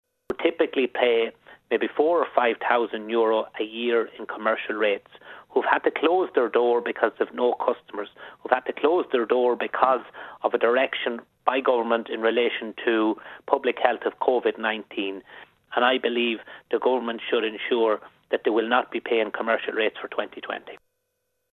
But Fianna Fail’s business spokesperson Robert Troy says more needs to be done: